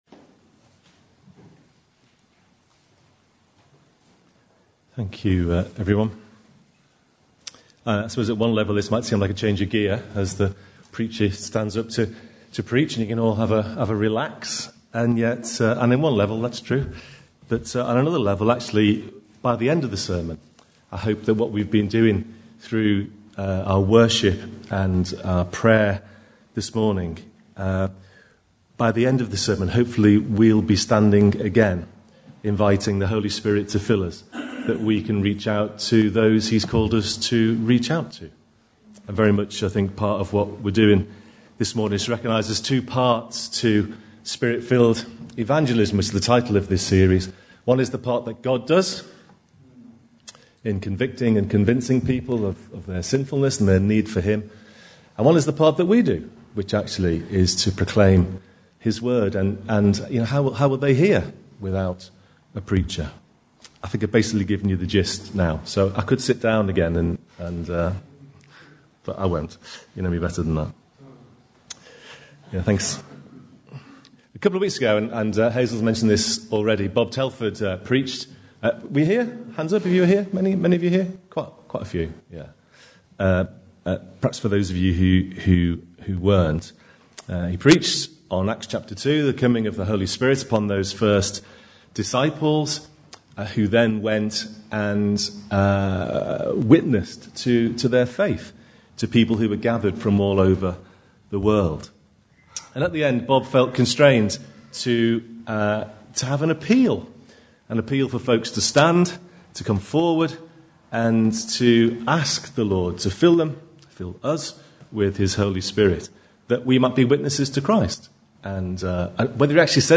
Prayers